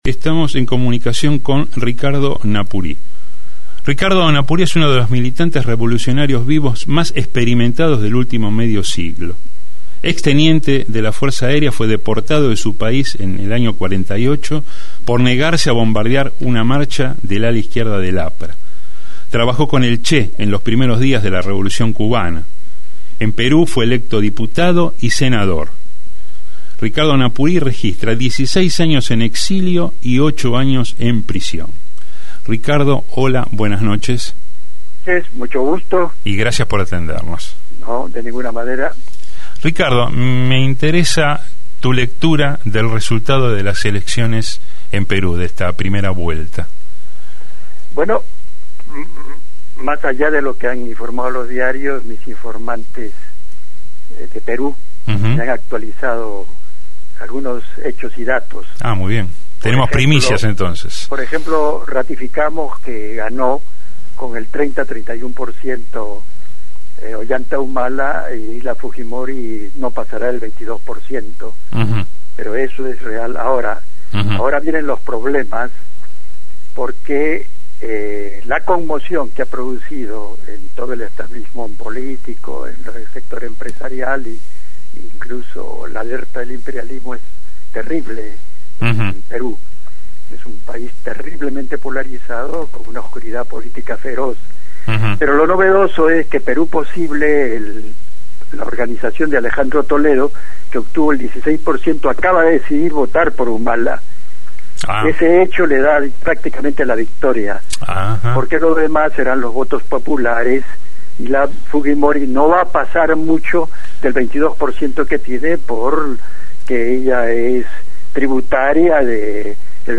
Entrevista-a-Ricardo-Napuri.mp3